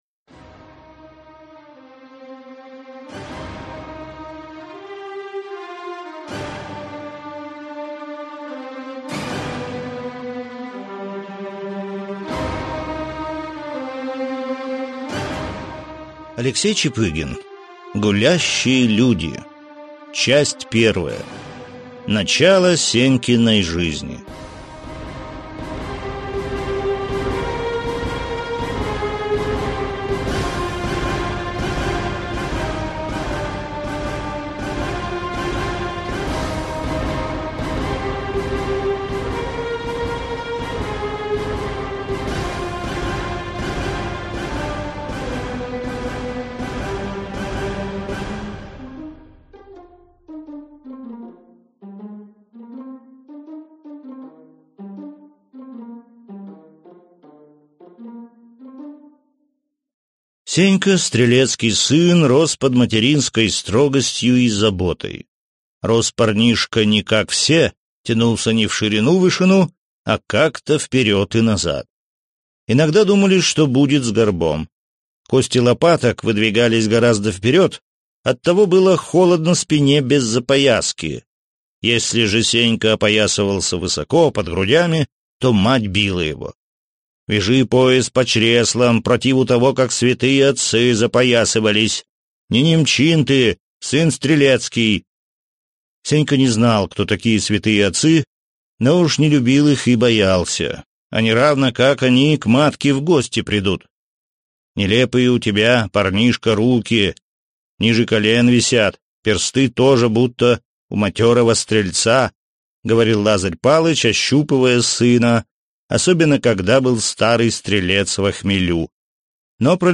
Аудиокнига Гулящие люди | Библиотека аудиокниг